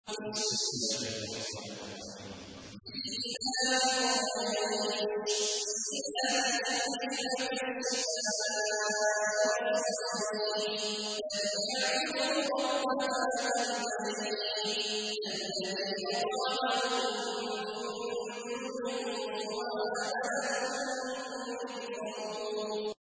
تحميل : 106. سورة قريش / القارئ عبد الله عواد الجهني / القرآن الكريم / موقع يا حسين